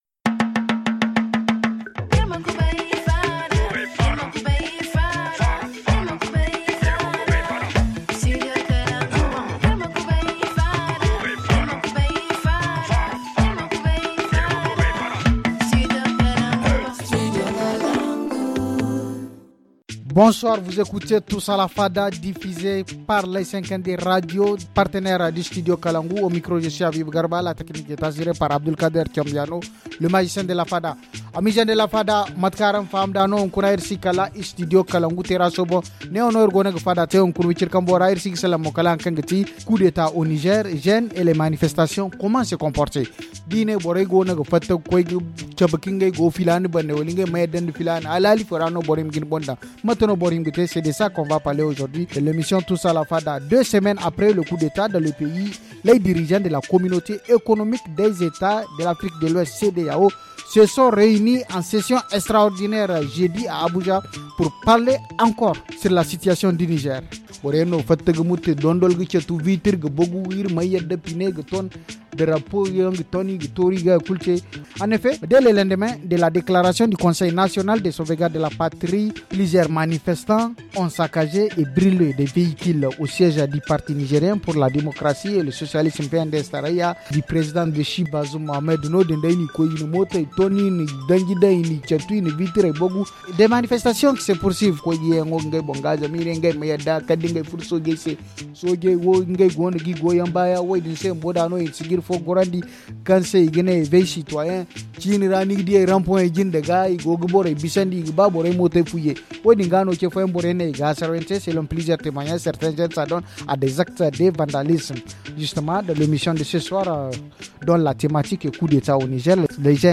Nous sommes au siège du studio kalangou avec nos amis jeunes qui vont se présenter dans un instant.